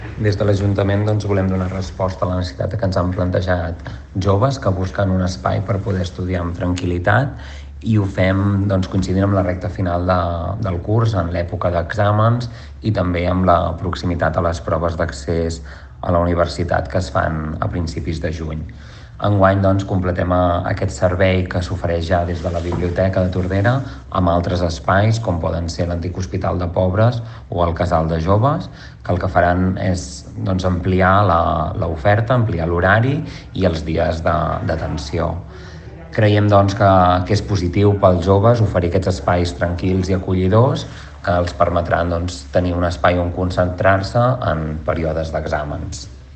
El regidor d’ensenyament, Pere Garcia considera que amb aquesta iniciativa volen donar resposta a una necessitat dels estudiants del municipi.